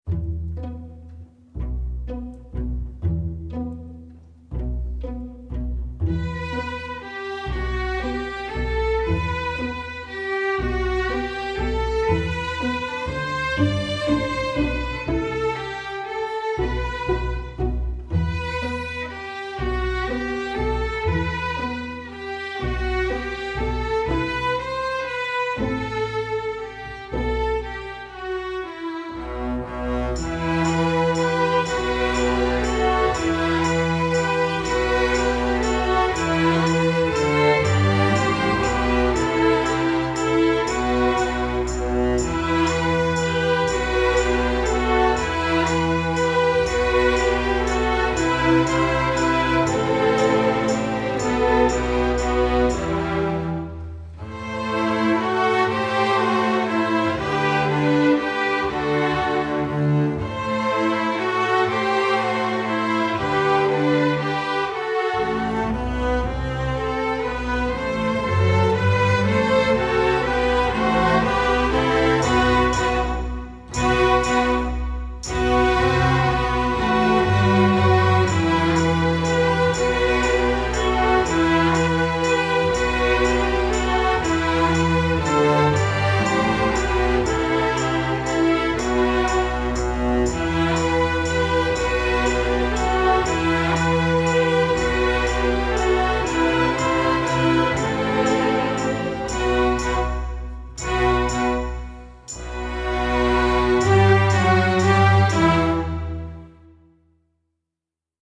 Instrumentation: string orchestra
folk, pop, rock, dance, instructional, children